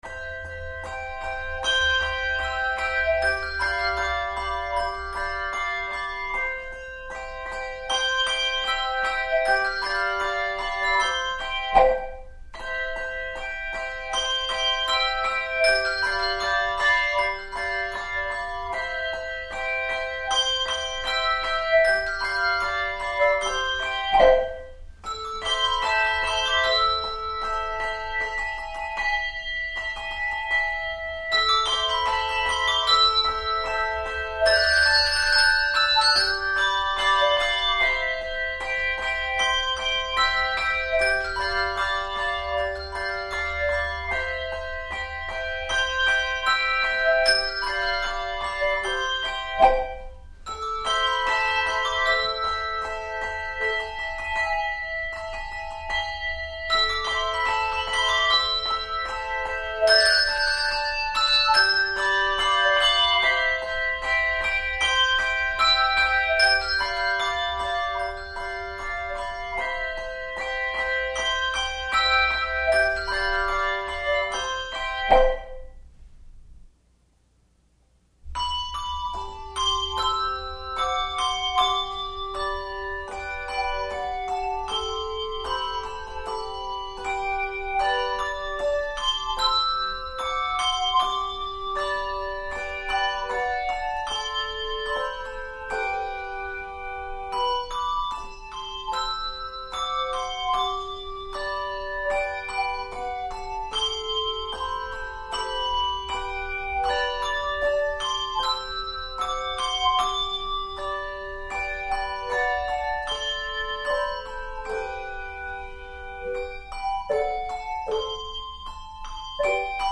Handbell Quartet
No. Octaves 3 Octaves